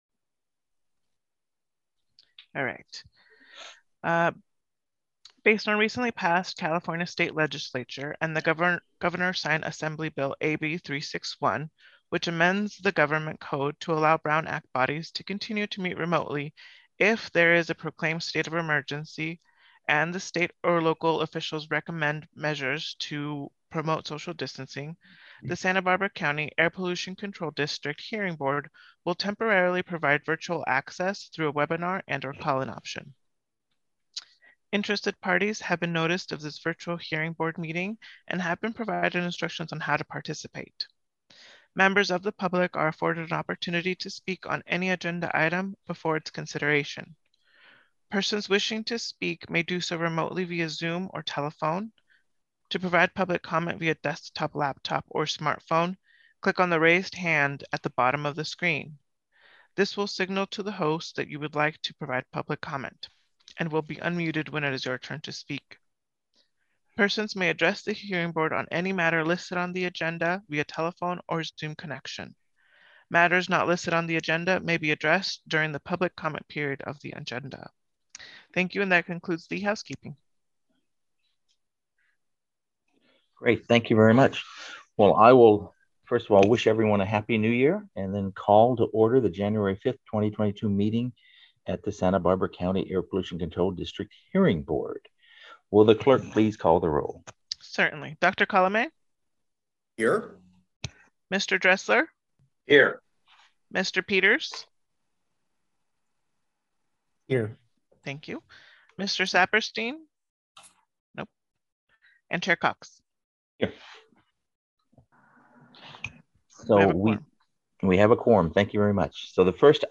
*REMOTE VIRTUAL PARTICIPATION ONLY